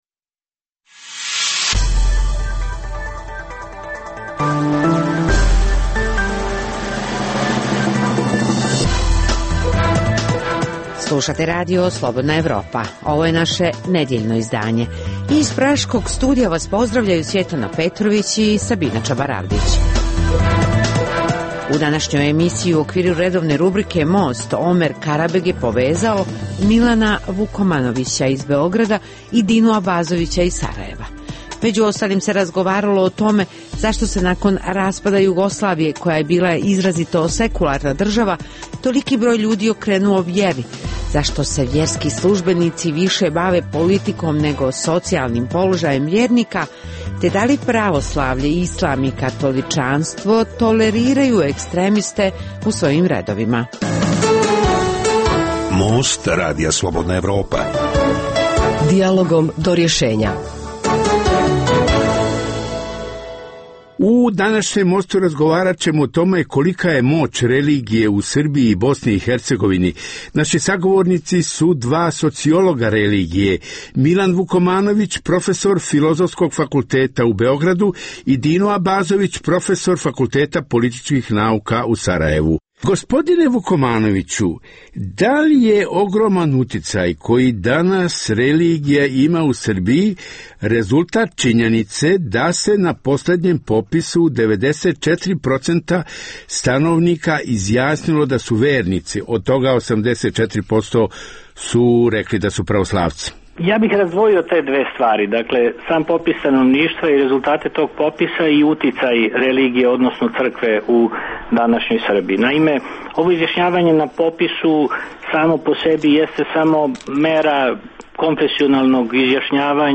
U Mostu koji objavljujemo u ovoj emisiji razgovaralo se o tome koliki je uticaj religije u Srbiji i Bosni i Hercegovini. Sagovornici su bili sociolozi religije